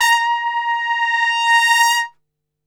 A#3 TRPSWL.wav